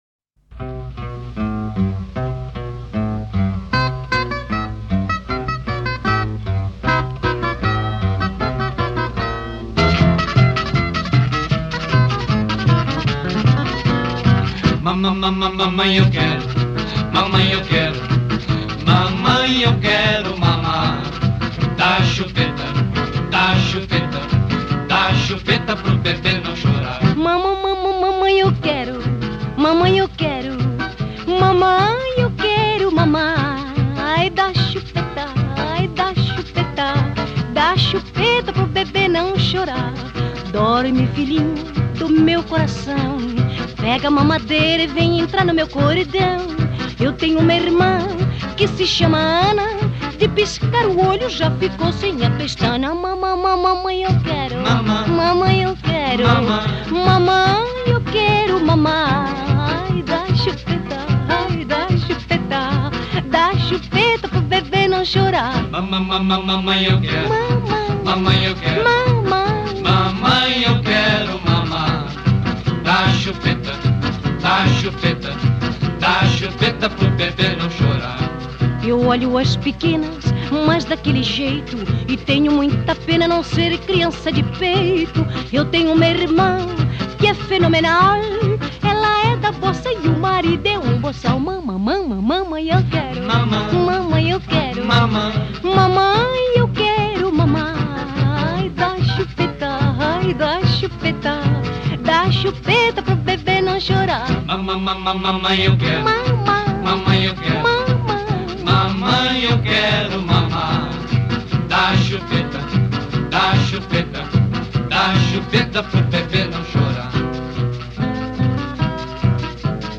Silly, fun music.